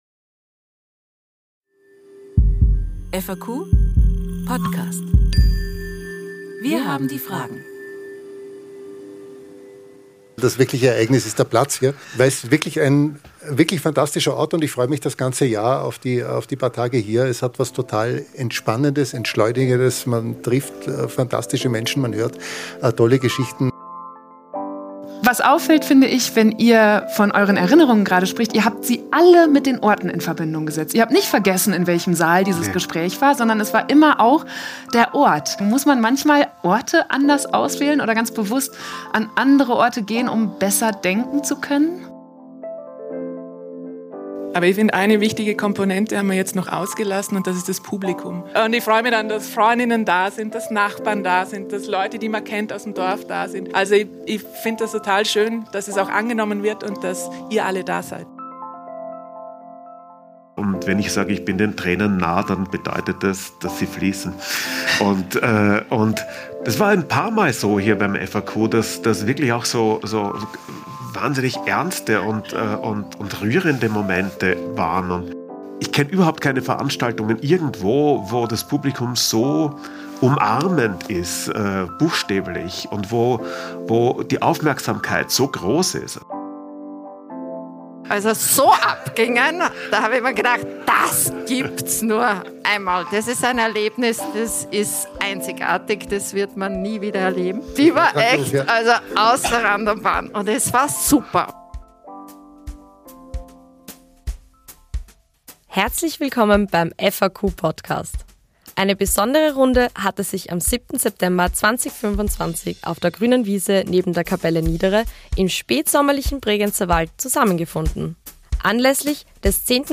Wie hat alles angefangen, und was beschäftigt sie heute? Eine gute Runde, aufgezeichnet auf der grünen Wiese, jetzt für überall zum Nachhören.